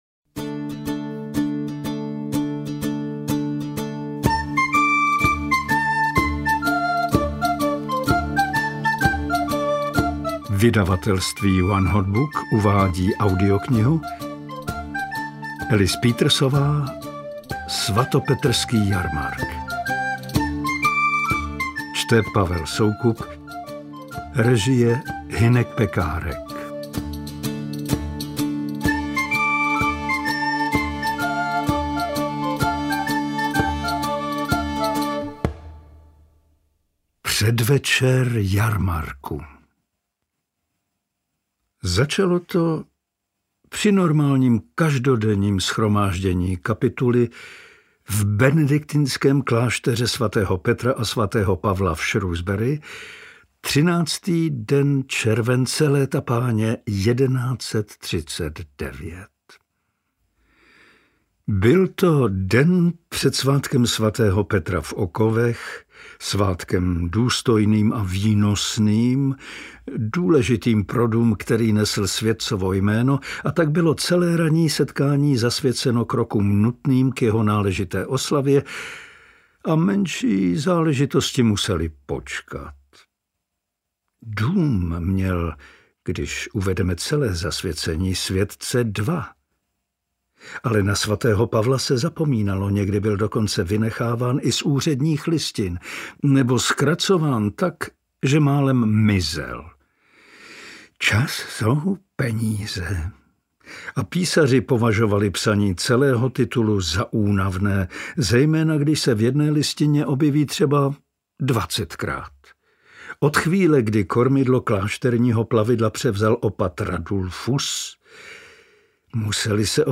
Interpret:  Pavel Soukup
AudioKniha ke stažení, 31 x mp3, délka 9 hod. 35 min., velikost 518,0 MB, česky